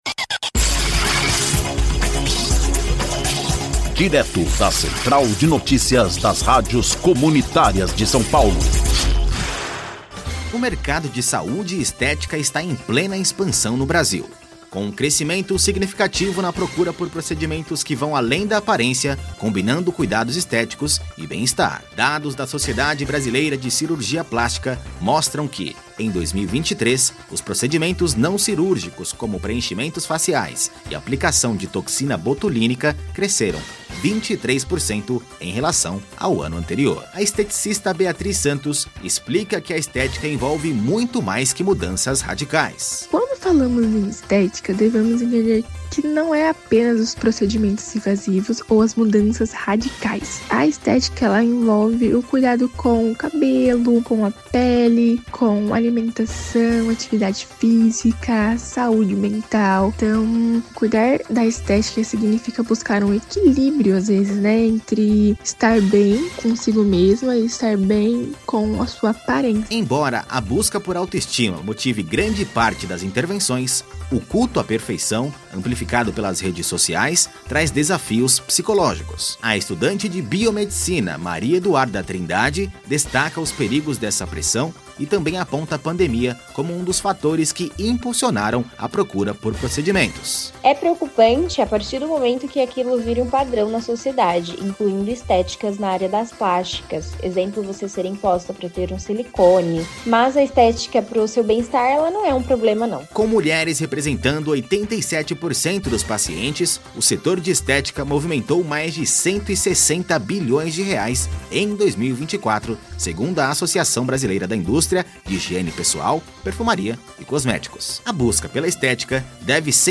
INFORMATIVO: